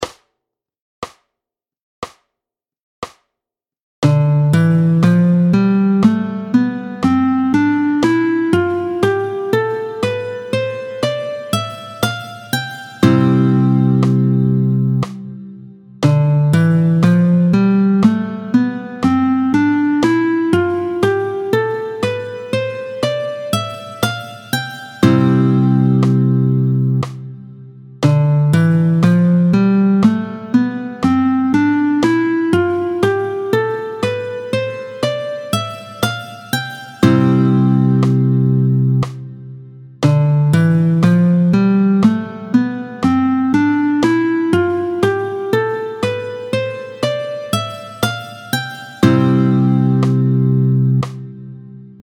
26-08 Doigté 2, Do majeur, tempo 60